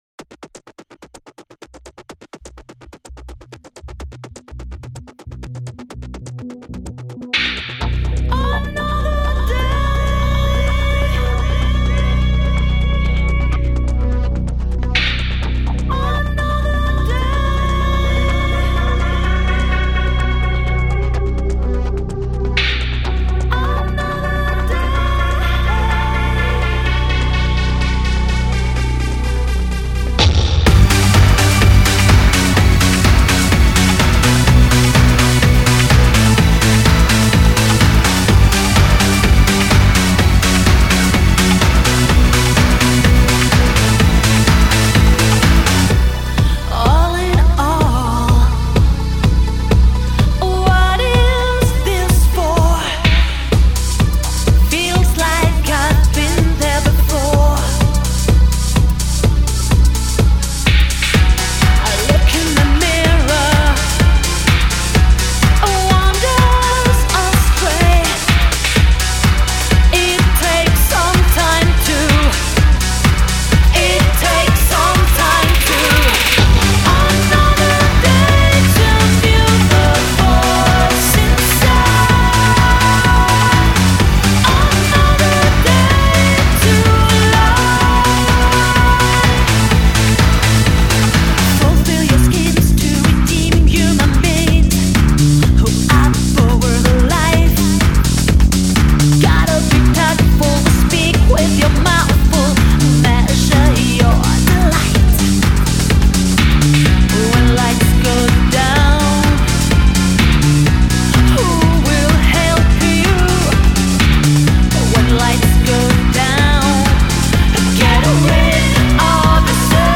Vocals
Lead Guitars, Keyboard, Programming